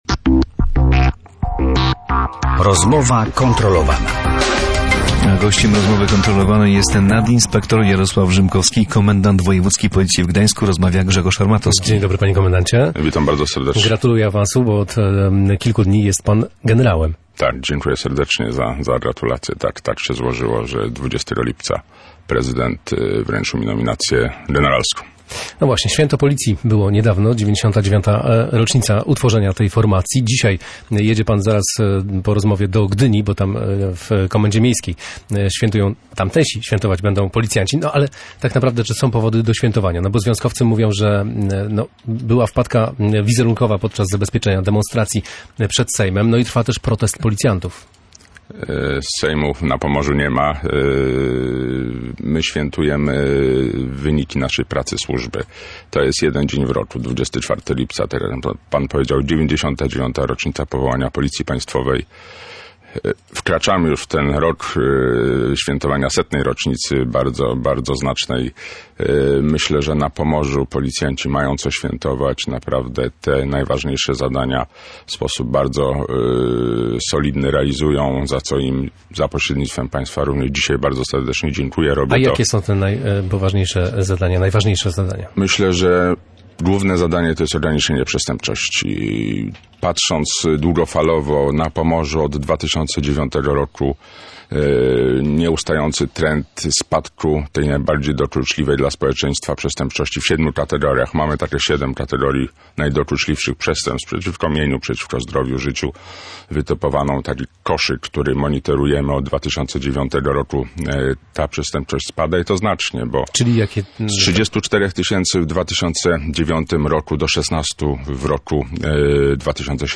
O tym mówił w Radiu Gdańsk nadinspektor Jarosław Rzymkowski – komendant wojewódzki policji w Gdańsku.